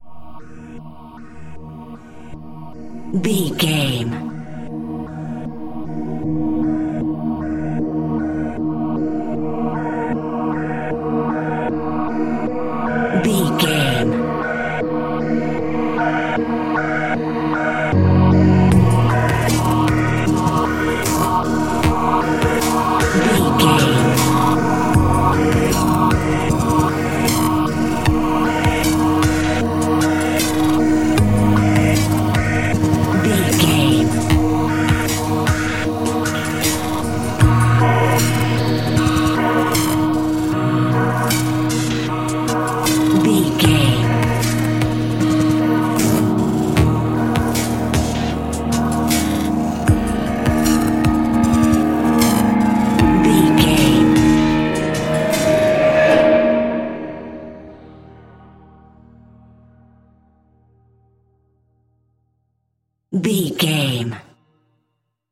Epic / Action
Fast paced
In-crescendo
Ionian/Major
D♯
dark ambient
synths